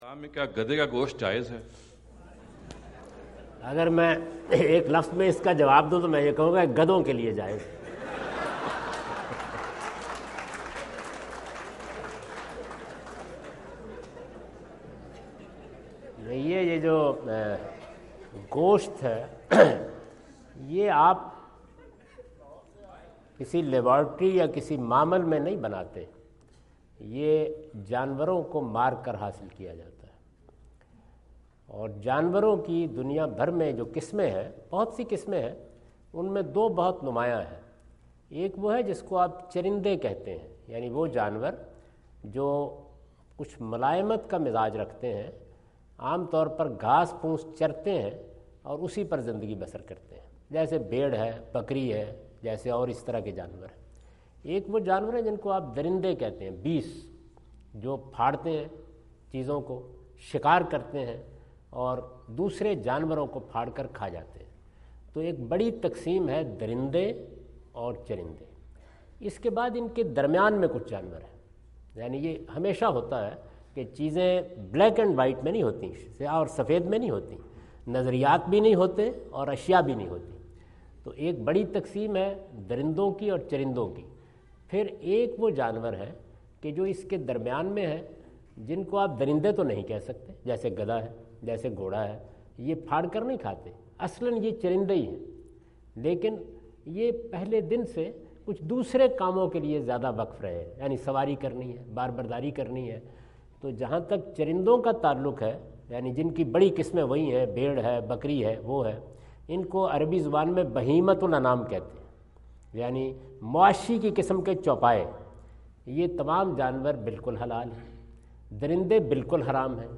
Javed Ahmad Ghamidi answer the question about "Is Donkey Meat Permissible in Islam?" asked at Aapna Event Hall, Orlando, Florida on October 14, 2017.